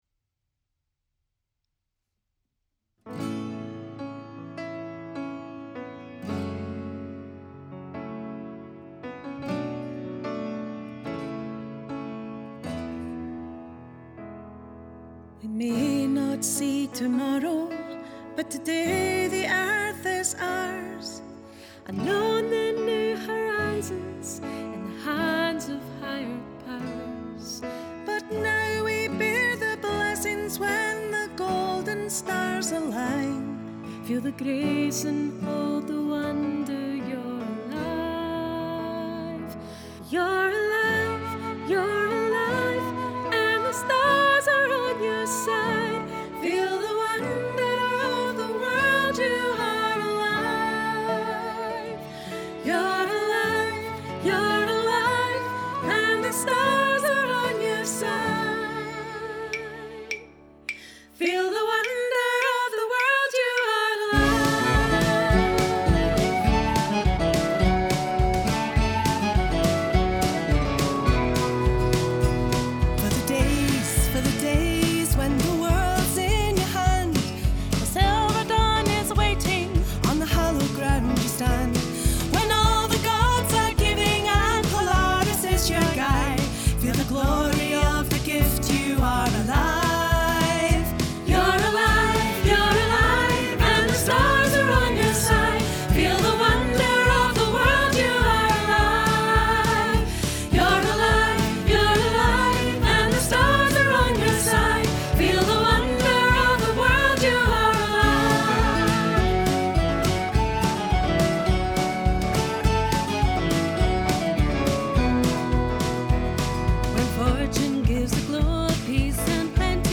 Track with guide vocals